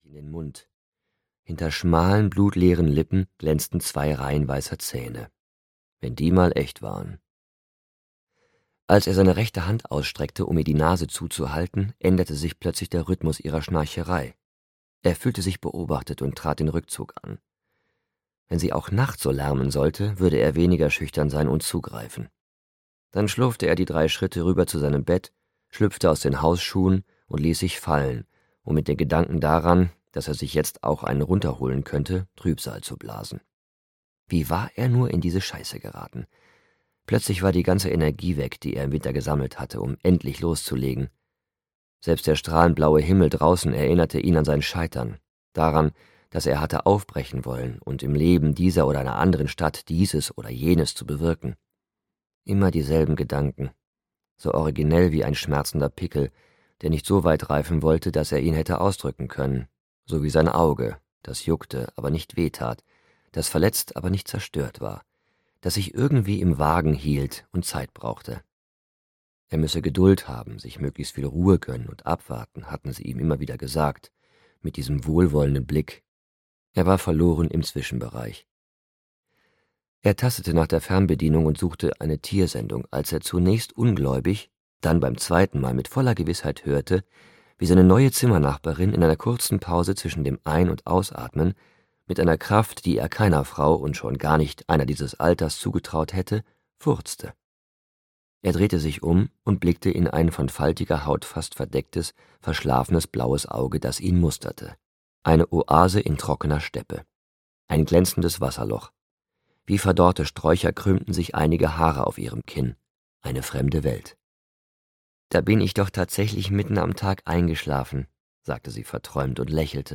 Frau Ella - Florian Beckerhoff - Hörbuch